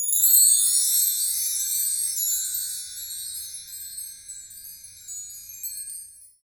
Index of /90_sSampleCDs/Roland LCDP03 Orchestral Perc/PRC_Wind Chimes2/PRC_Marktree